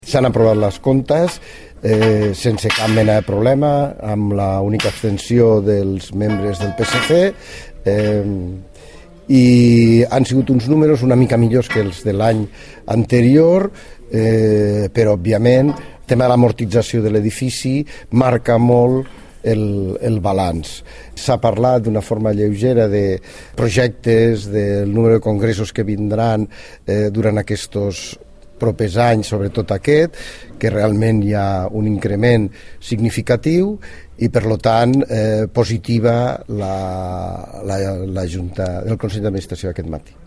Tall de veu del tinent d'alcalde i president del CNC, Paco Cerdà, sobre el Consell d'Administració del Centre de Negocis i Convencions